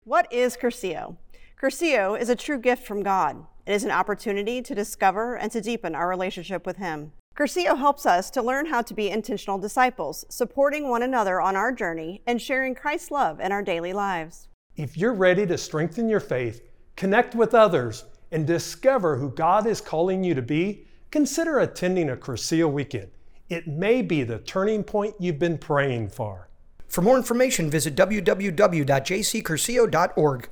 Note What is Cursillo Radio Commercial What is Cursillo?